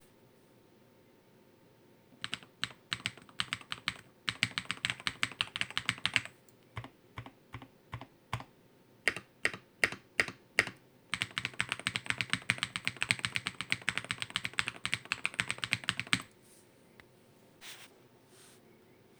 Bên trong bàn phím được lấp đầy hoàn toàn, bao gồm hai lớp silicone hấp thụ rung động, mang lại âm thanh nhấn phím ấm và dễ chịu.
Dưới đây là tiếng ồn tạo ra khi gõ:
Là loại tuyến tính (không có hiệu ứng clicky), các switch Yellow không phát ra tiếng ồn khi kích hoạt. Chỉ có điểm cuối hành trình và điểm dừng của phím sẽ phát ra tiếng động khô và sắc trên các phím chính. Phím Enter có âm thanh rõ ràng hơn trong khi phím Spacebar có âm thanh phát ra nhiều cộng hưởng hơn.